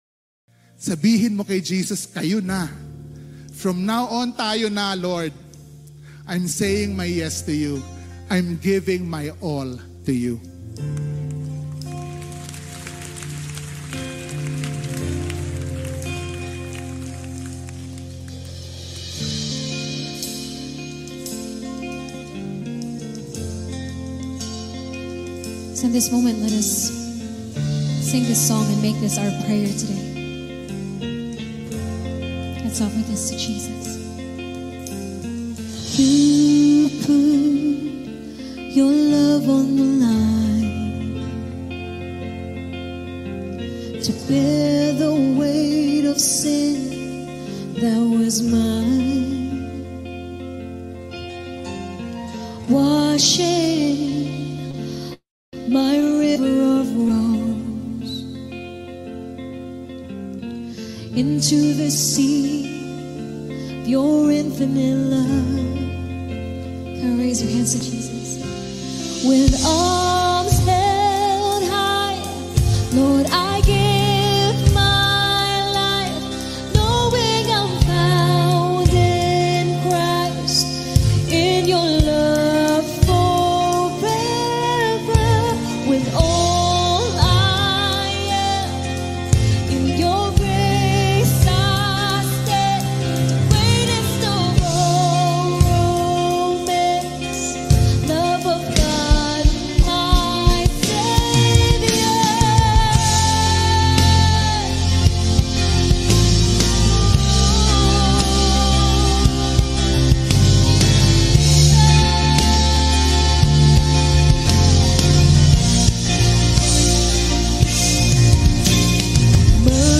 Drum Cover